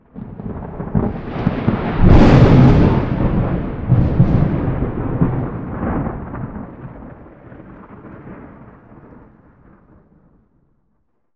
new_thunder4_hec.ogg